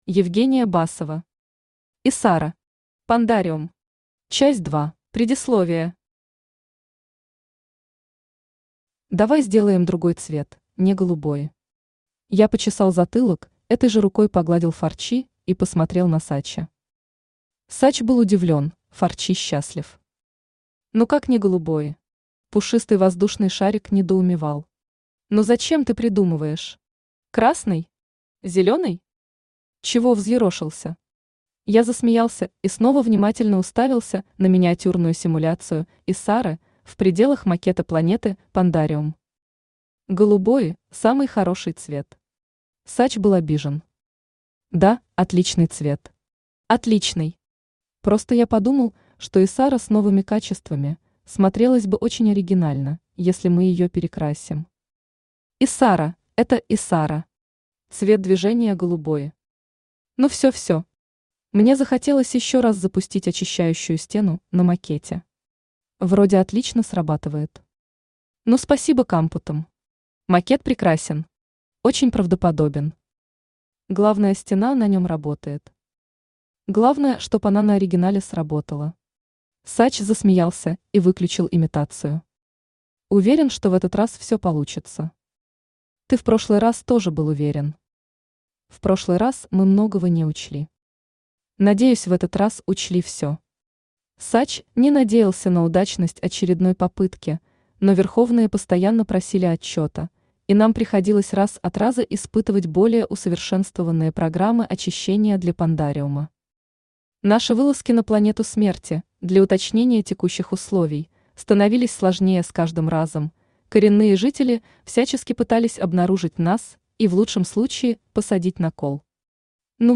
Читает: Авточтец ЛитРес
Аудиокнига «Исара. Пандариум. Часть 2». Автор - Евгения Басова.